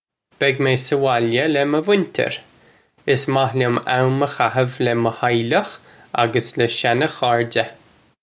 Pronunciation for how to say
Beg may suh wol-ya luh muh vwinchir. Iss mah lyum am uh khahiv luh muh how-lukh uggus luh shan-khar-juh.
This is an approximate phonetic pronunciation of the phrase.